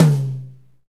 Index of /90_sSampleCDs/Northstar - Drumscapes Roland/KIT_R&B Kits/KIT_R&B Dry Kitx
TOM H H HI0Q.wav